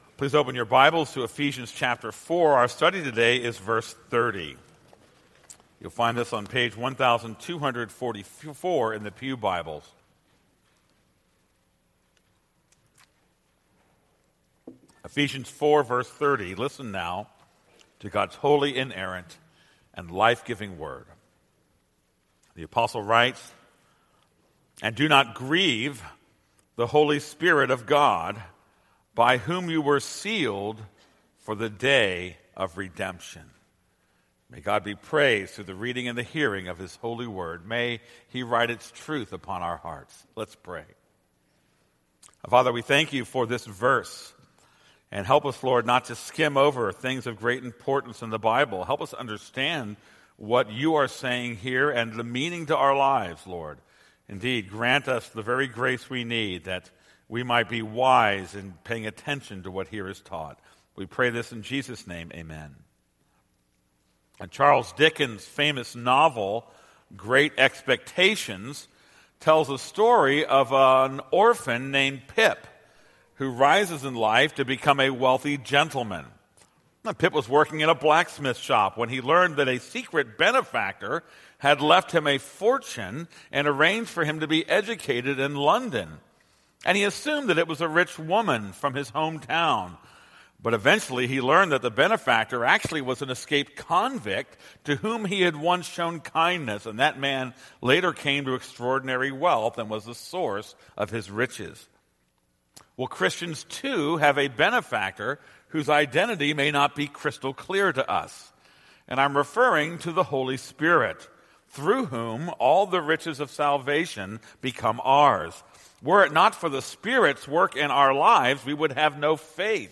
This is a sermon on Ephesians 4:30.